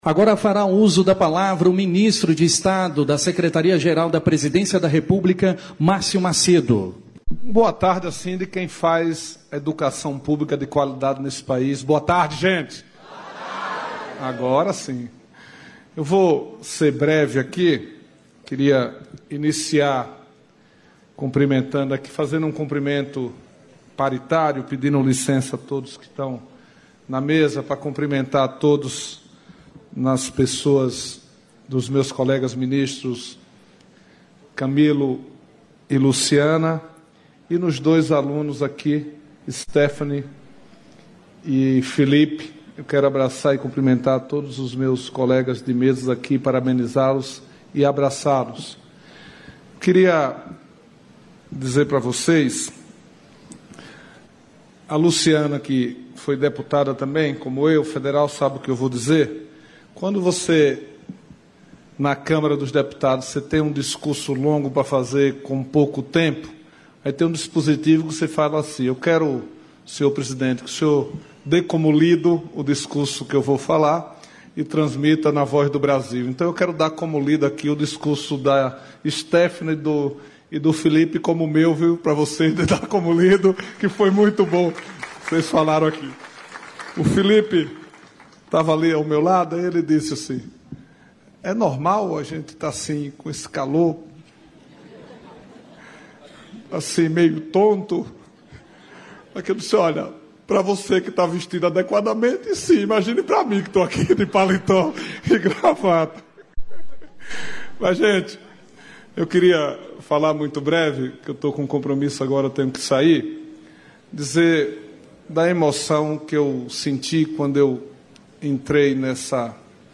Íntegra da fala do ministro Márcio Macêdo, da Secretaria-Geral da Presidência; do presidente dos Correios, Fabiano Santos; da ministra da Ciência, Tecnologia e Inovação, Luciana Santos, e do ministro da Educação, Camilo Santana, na abertura 4ª edição da Semana Nacional da Educação Profissional e Tecnológica, nesta terça-feira (26), em Brasília.